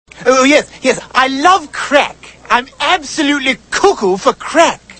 • Voice Tones Ringtones